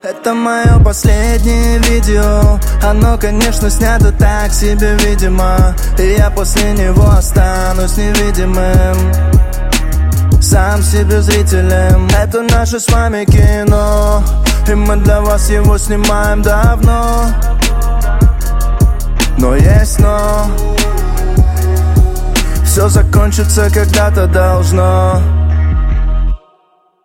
• Качество: 128, Stereo
мужской вокал
душевные
грустные
русский рэп